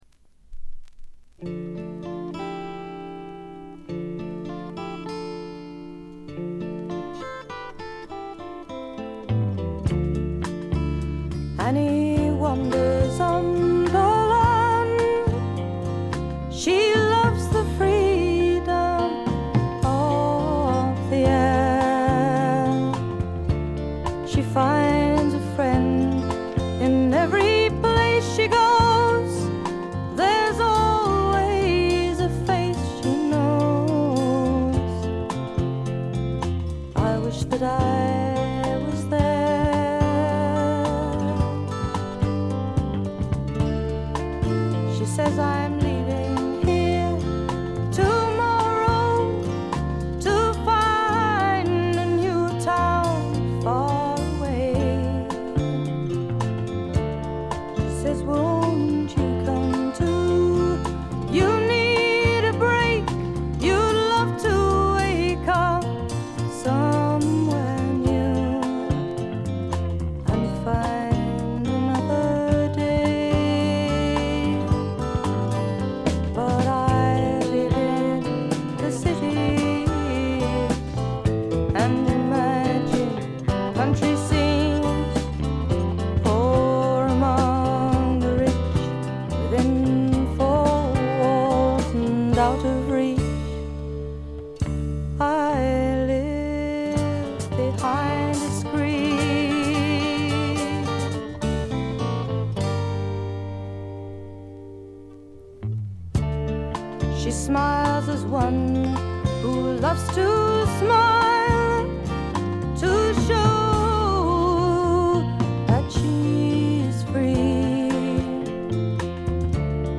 軽微なバックグラウンドノイズ、チリプチ、散発的な軽いプツ音が少し。
英国フォークロックの基本中の基本！！
試聴曲は現品からの取り込み音源です。